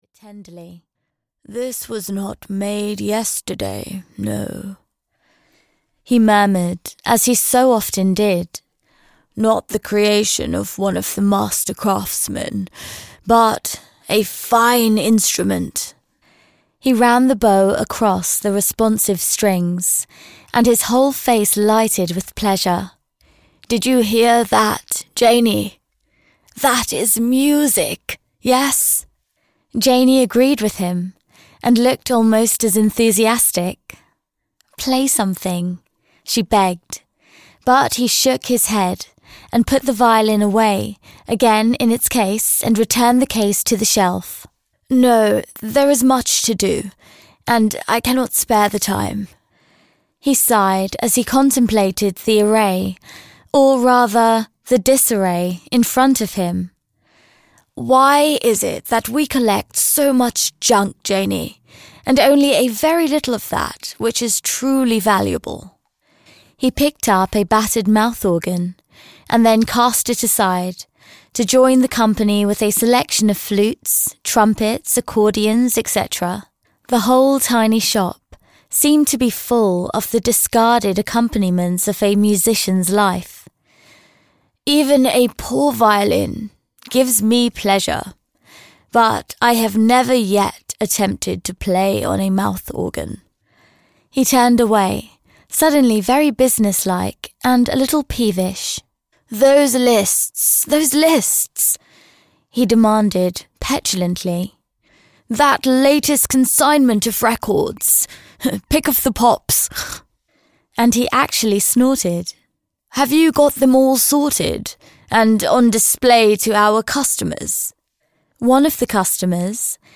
Audio knihaPathway of Roses (EN)
Ukázka z knihy